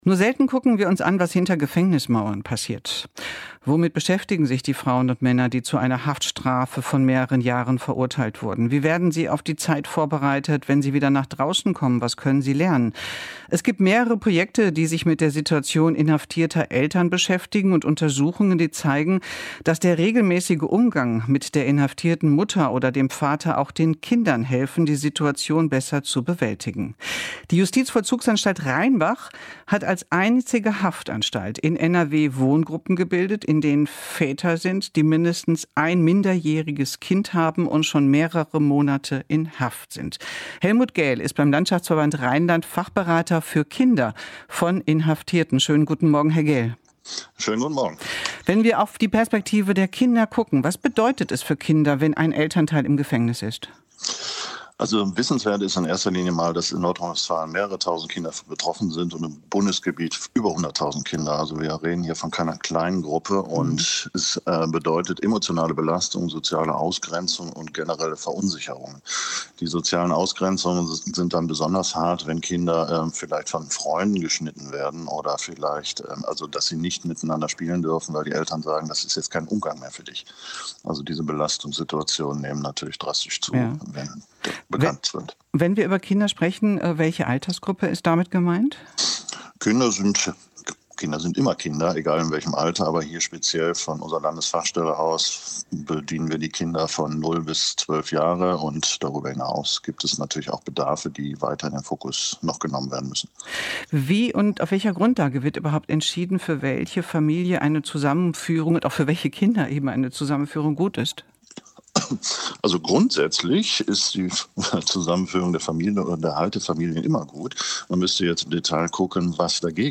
WDR 5 Interview: Väter hinter Gittern (14.05.2025)